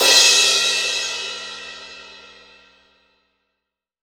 Index of /90_sSampleCDs/AKAI S6000 CD-ROM - Volume 3/Crash_Cymbal1/18_22_INCH_CRASH
ROCK18CRS1-S.WAV